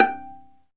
Index of /90_sSampleCDs/Roland - String Master Series/STR_Viola Solo/STR_Vla Pizz